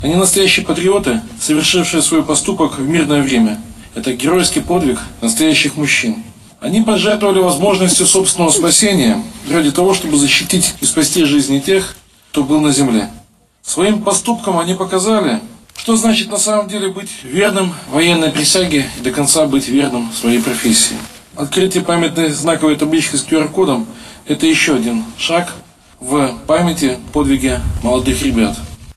В День защитников Отечества и 105-летия Вооруженных Сил  Республики Беларусь в Барановичах у памятника погибшим лётчикам — героям Беларуси Андрею Ничипорчику и Никите Куконенко состоялся митинг.